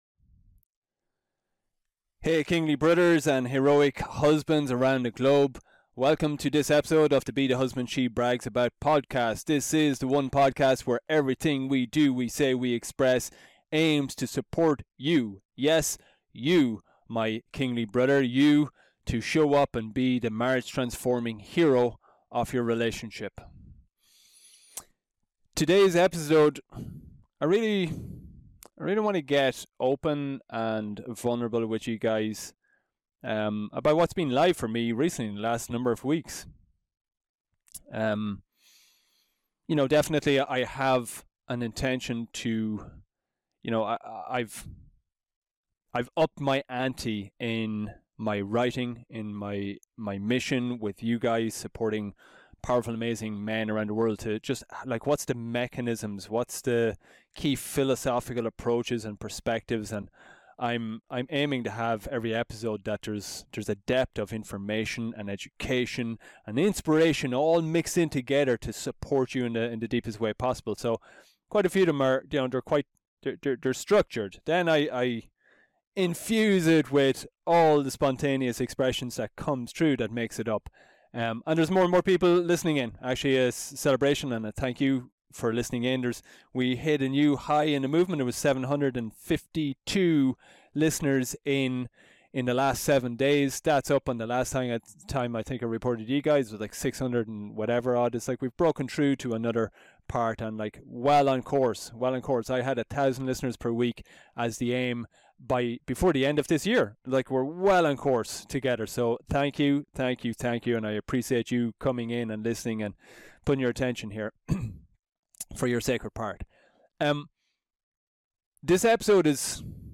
A raw and vulnerable opening up to recent intense marriage shifts I've been experiencing inside myself and then in conversation with my Queen.